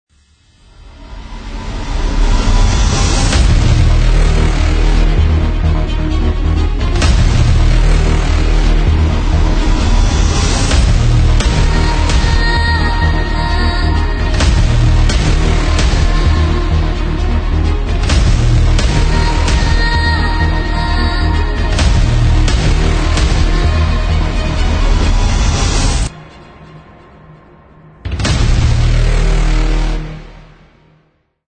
描述：史诗，强大，强烈，好莱坞风格的音乐标志，预告片和预告片。